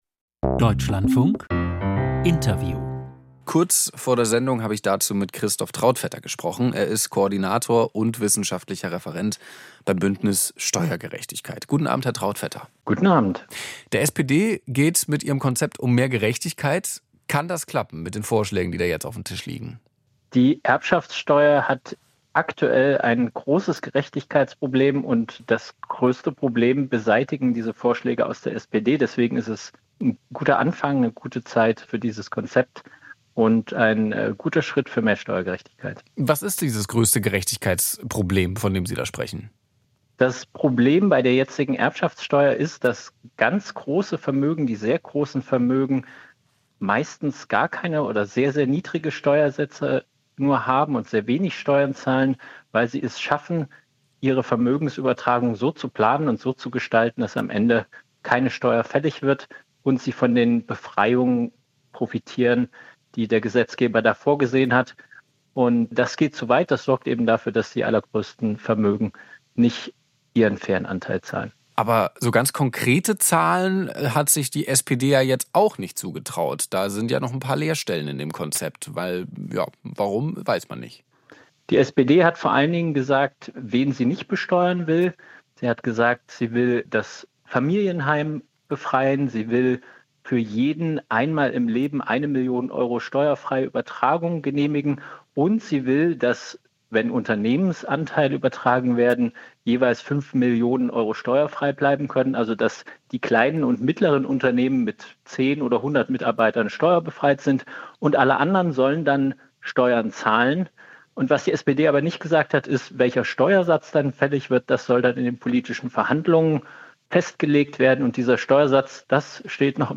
Erbschaftssteuer - Interview